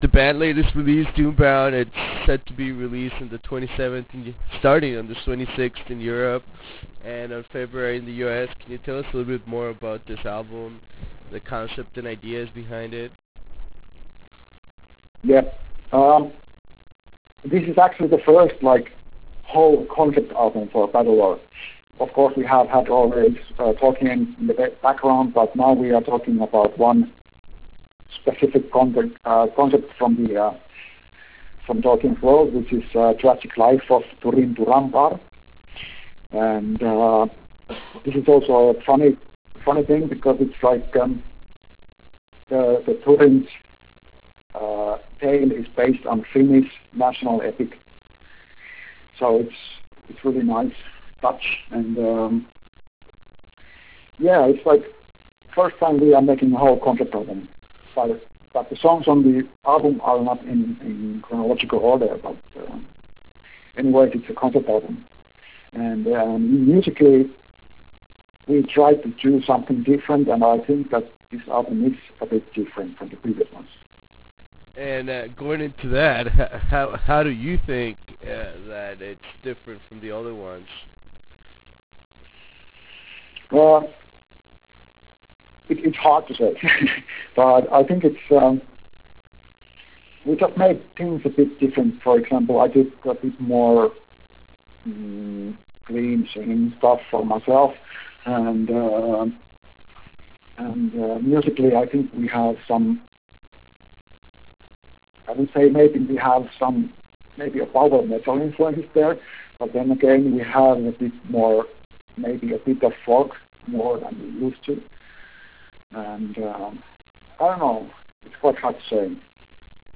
Interview with Battlelore